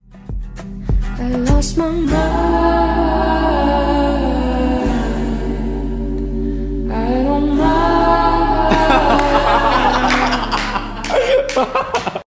*Risada do Taylor Lautner*Meus níveis sound effects free download